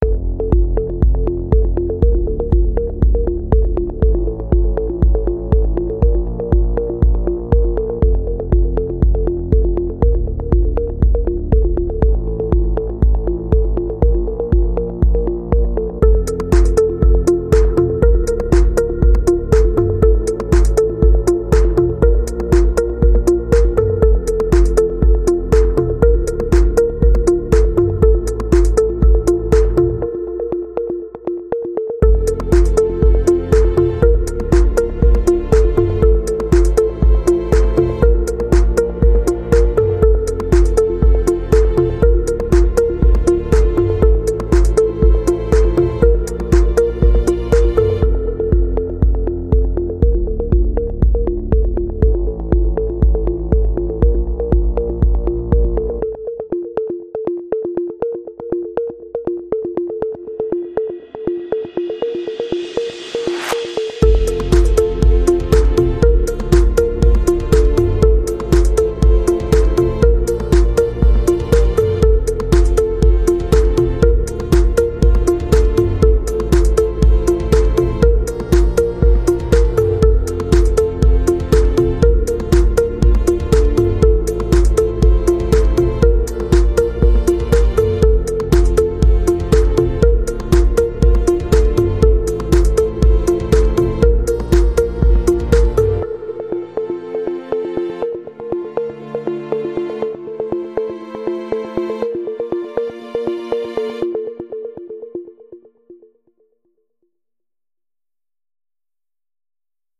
Simple beats and delayed pluck sounds